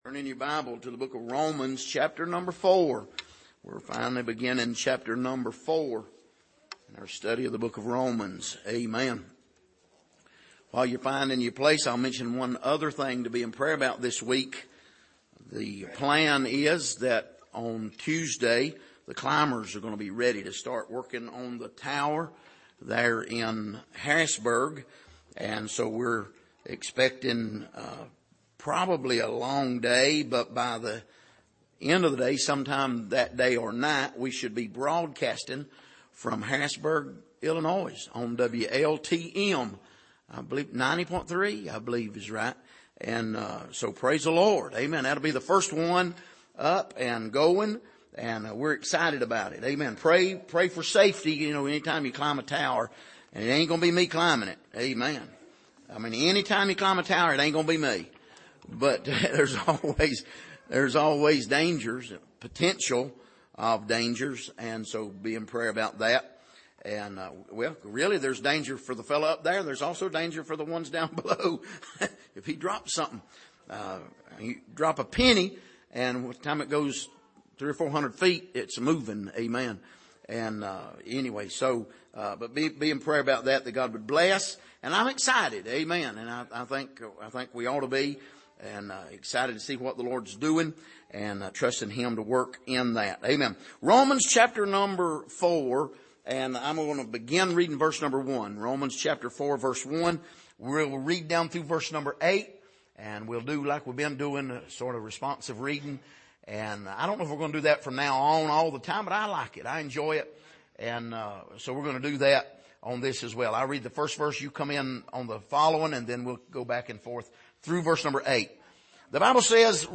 Passage: Romans 4:1-8 Service: Sunday Morning